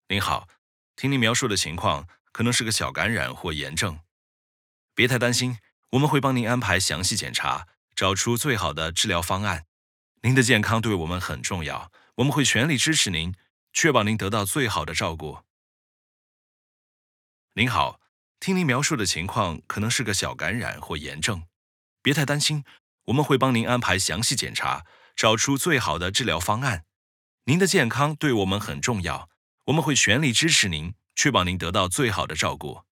Commercial, Young, Natural, Friendly, Warm
E-learning